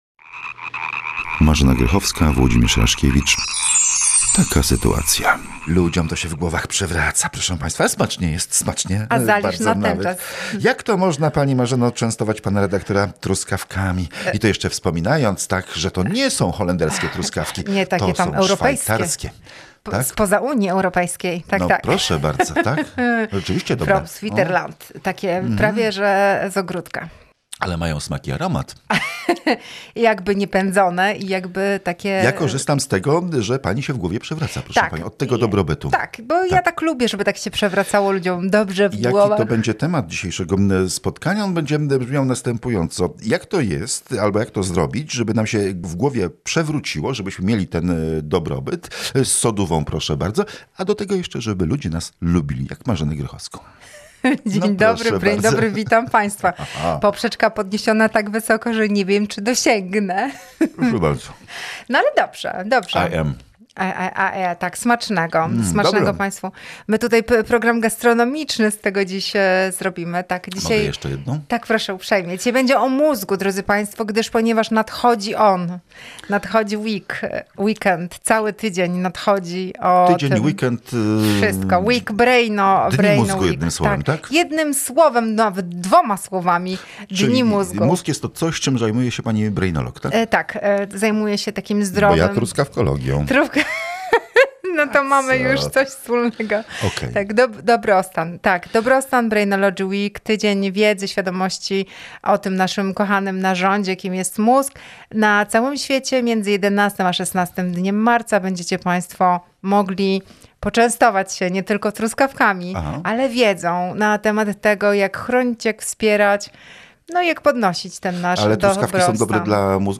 Kobieta z ogromnym dystansem do siebie, pogodą ducha, zabawnie potrafi opowiadać o skomplikowanych relacjach, bo więcej można wynieść z życzliwej rozmowy niż umoralniającego, dętego wykładu.
Lubi w pytaniach dziennikarskich podważać utarte schematy myślenia, zadziorne, ale bez złośliwości, uśmiechać się do „prawd absolutnych”.